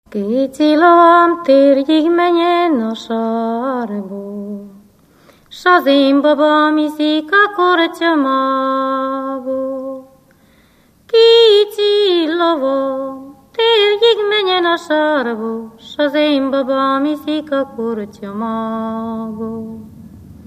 Moldva és Bukovina - Moldva - Lészped
ének
Stílus: 3. Pszalmodizáló stílusú dallamok
Kadencia: 7 (b3) 1 1